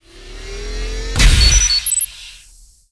b_marine_deploy.wav